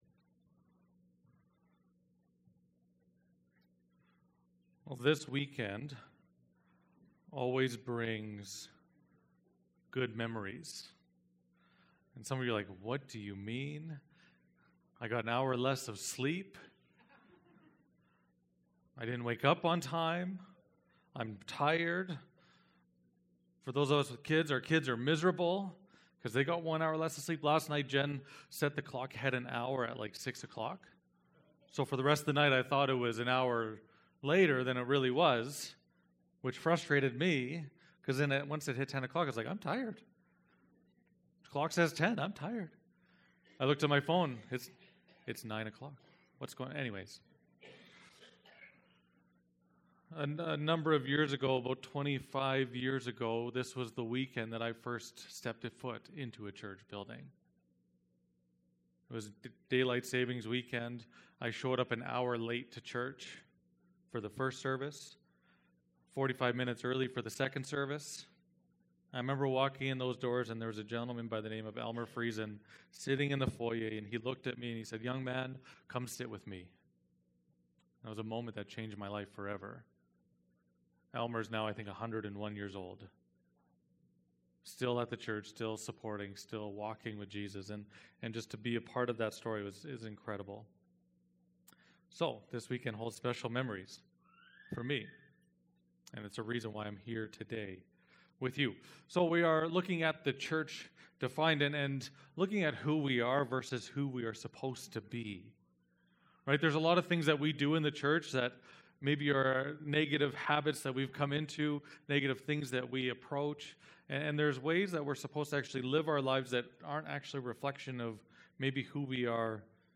Download Download THE CHURCH: Defined - Who We Are. Who We Should Be. Current Sermon THE CHURCH